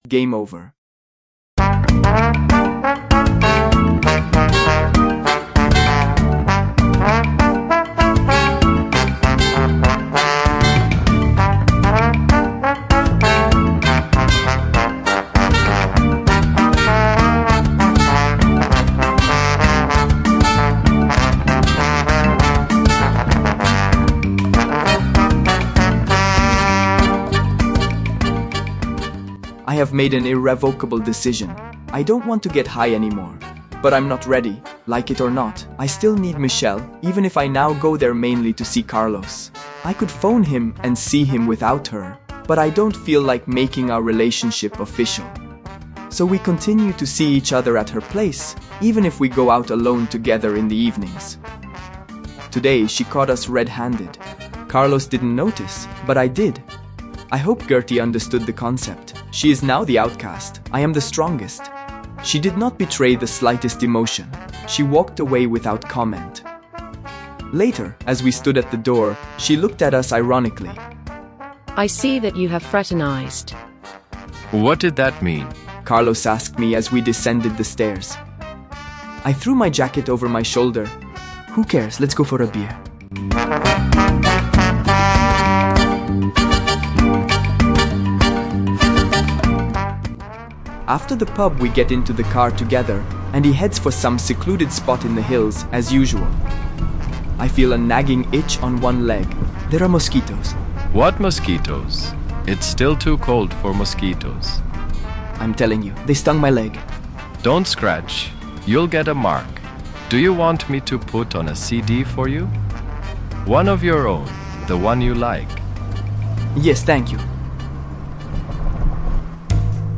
During the episode you can hear songs by Michael Land taken from "The Secret of Monkey Island", "Gouge away" by the Pixies and a cover of "I am" by Jamie Campbell Bower.